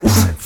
Dog Sneeze Sound - Sound Effect Button